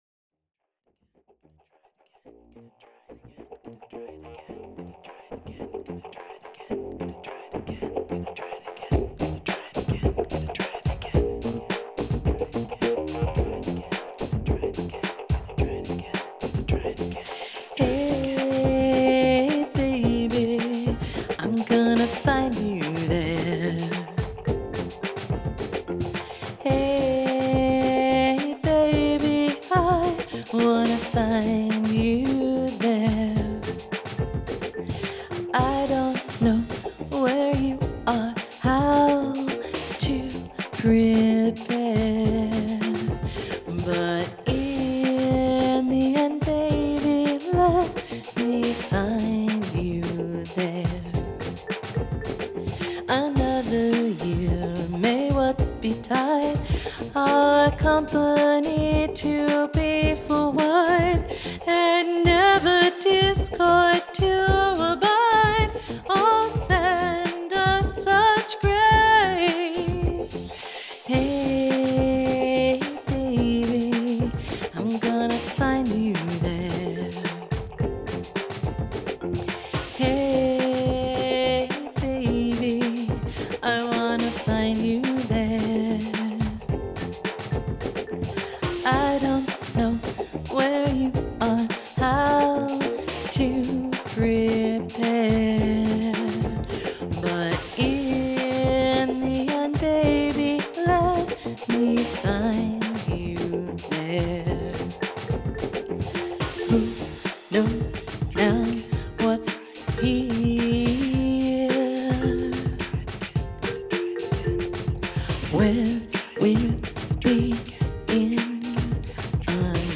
Sometimes you hear a great funk riff.*
And then you find a little plucky hook.
Drums autogenerated with GarageBand.